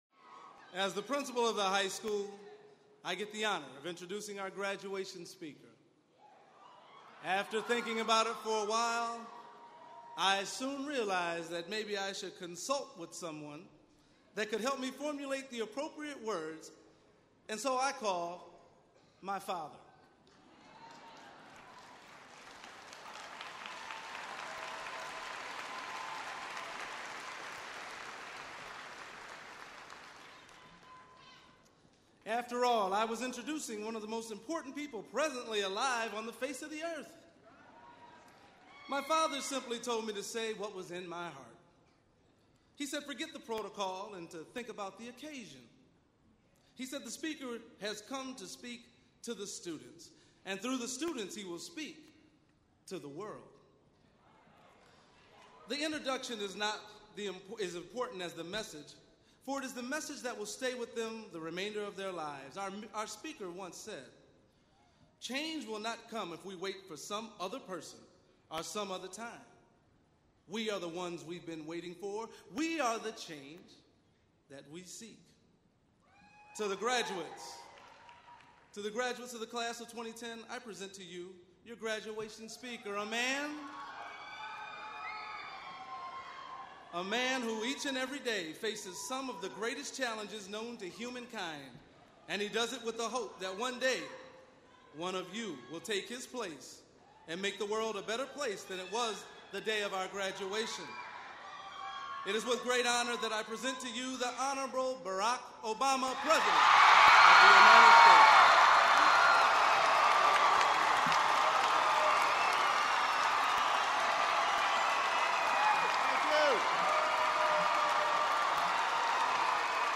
President Barack H. Obama delivers the commencement address at Kalamazoo Central High School in Kalamazoo, MI. President Obama speaks about the importance of personal responsibility and giving back to the community. He also highlights the accomplishments of several former Kalamazoo High students and the school's champion boys basketball team.
Recorded at Kalamazoo Central High School, Kalamazoo, MI, June 7, 2010.